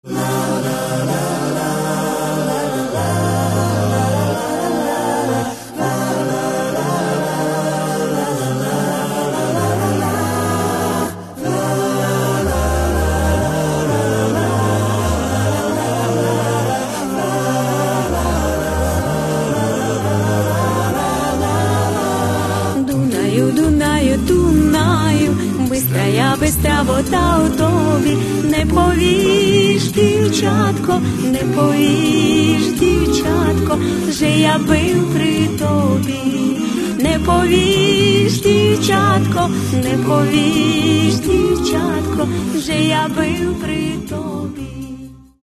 Каталог -> Народна -> Сучасні обробки